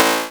error2.wav